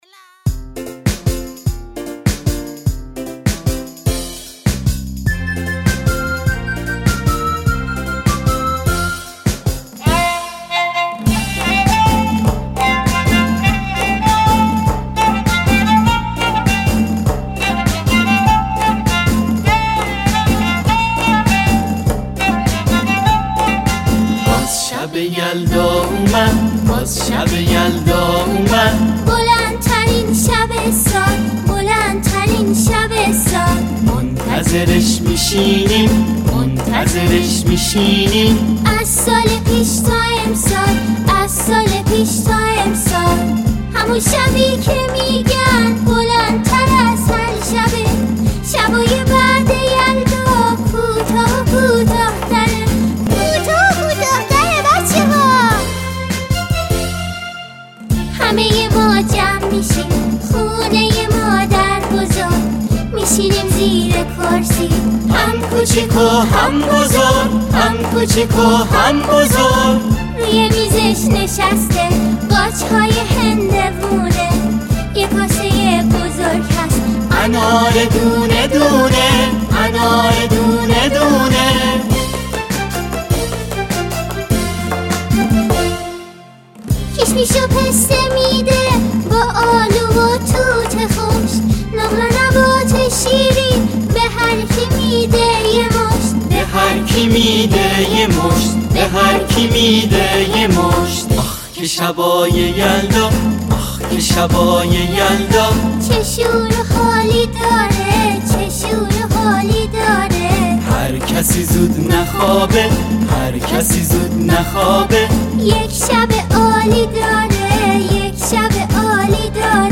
با صدای کودکانه